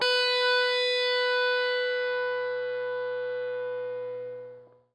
SPOOKY    AS.wav